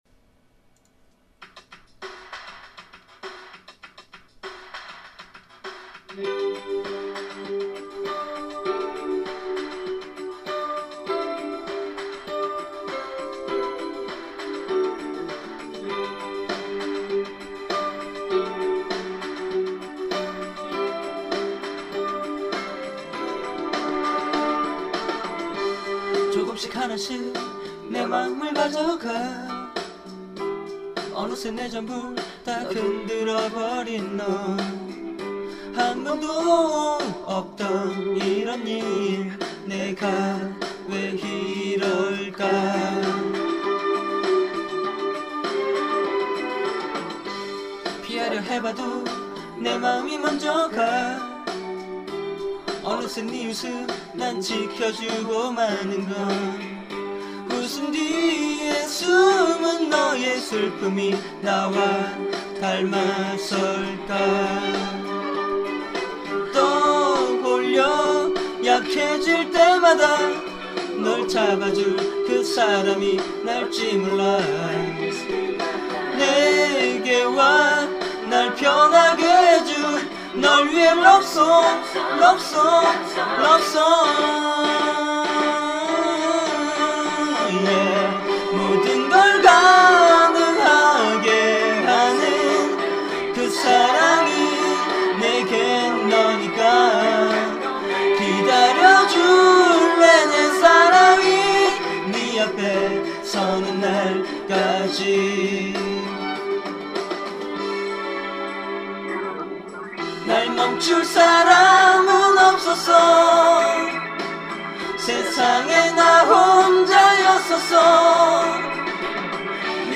직접 부른 노래를 올리는 곳입니다.